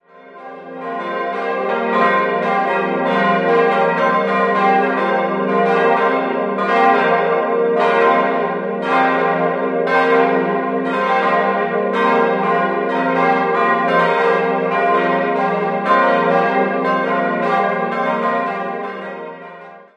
Pfarramt Auferstehungskirche, Amberg 4-stimmiges Gloria-TeDeum-Geläute: f'-g'-b'-c'' Die Glocken wurden 1961 vom Bochumer Verein für Gussstahlfabrikation gegossen.